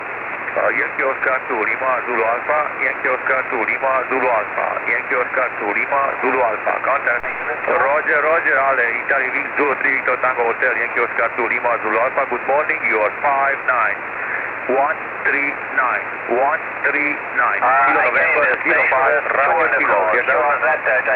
IARU VHF 09/2014 CATEGORIA 6 ORE